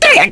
Lakrak-Vox_Damage_kr_02.wav